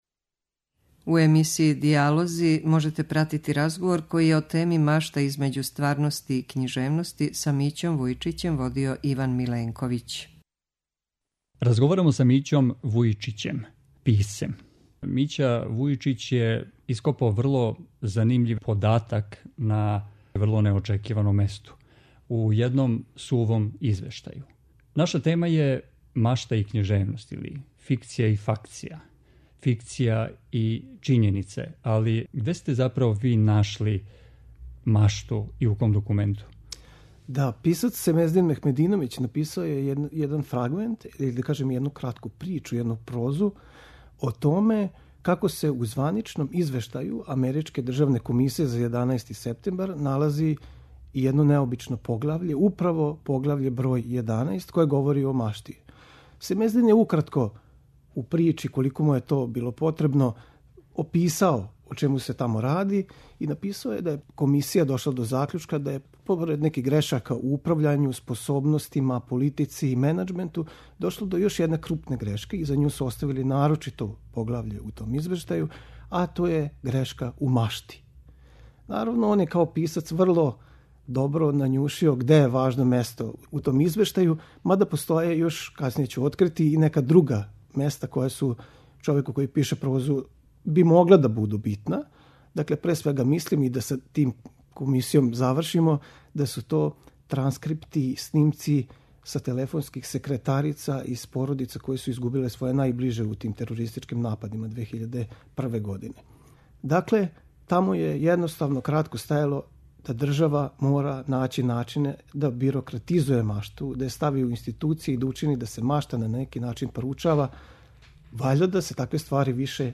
преузми : 19.96 MB Радијска предавања, Дијалози Autor: Трећи програм Из Студија 6 директно преносимо јавна радијска предавања.